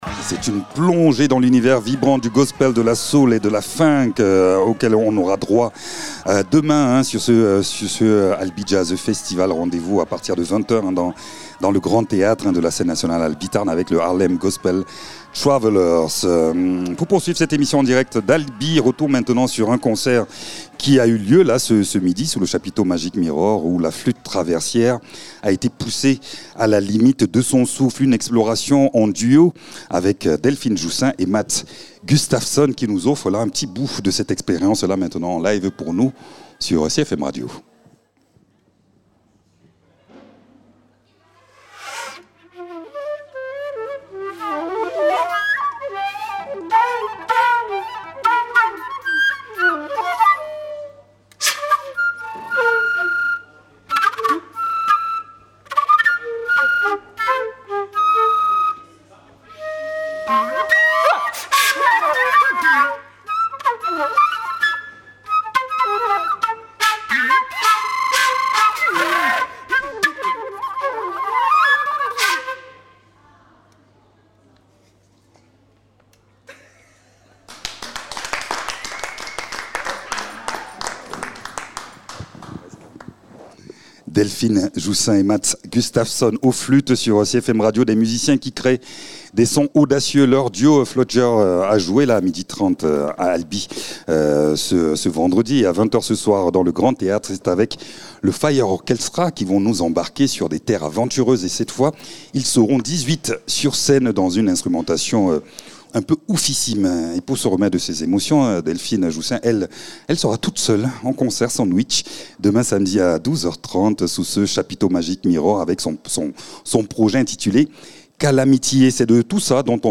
Une nouvelle histoire à haute tension, où règnent énergie intensive, beauté poétique, métal extrême, musique contemporaine, free jazz et noise !
flûtes, machines et voix